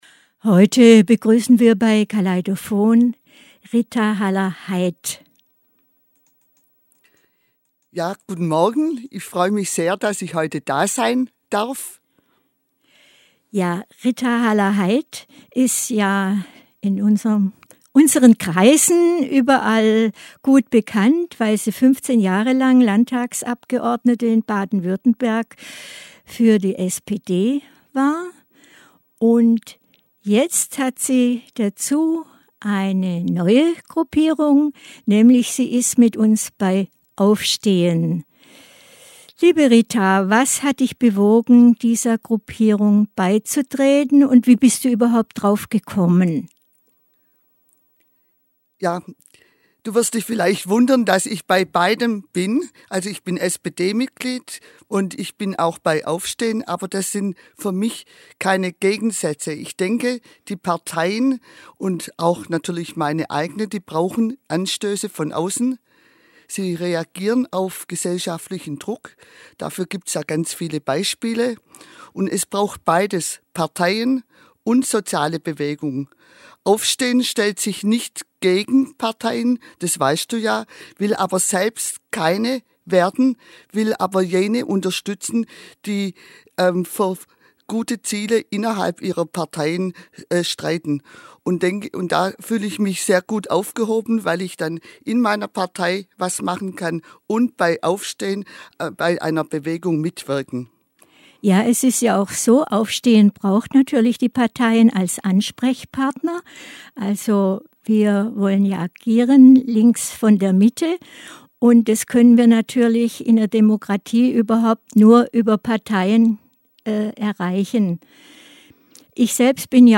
Interview mit Rita Haller-Haid zu "Aufstehen" Jean Ziegler: Was ist so schlimm am Kapitalismus?